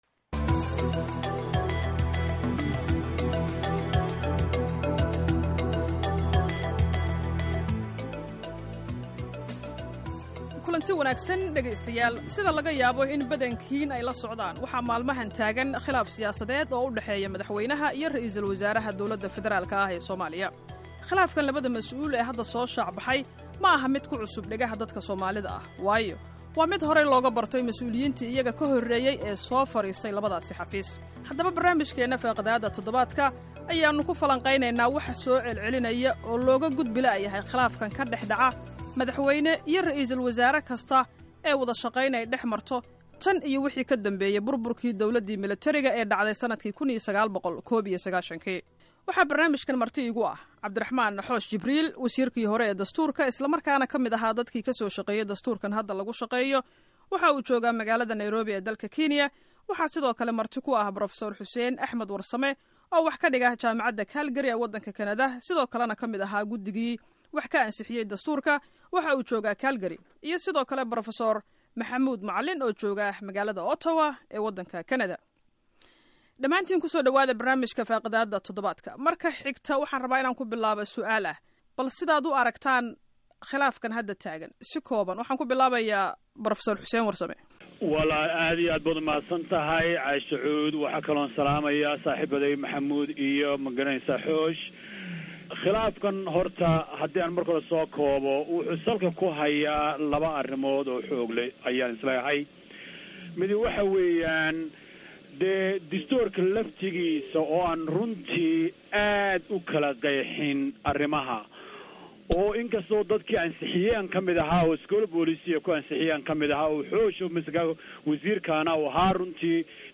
Idaacada afka Somaliga ku hadasha ee VOA ayaa maan 31 okt 2014 qabatay dood ku saabsan Khilaafka madaxda DFS ka dhx aloosan iyo sababta keenta ….